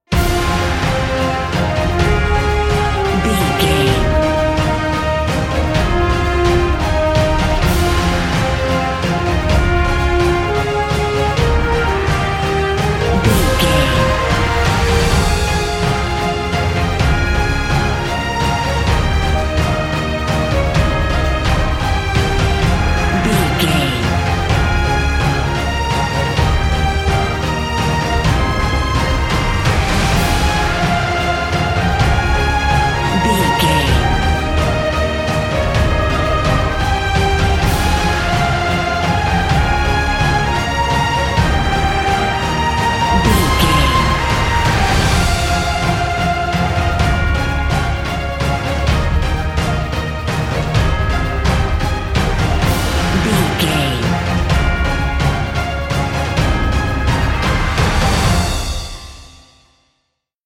Epic / Action
Fast paced
Aeolian/Minor
Fast
brass
drums
orchestra
synthesizers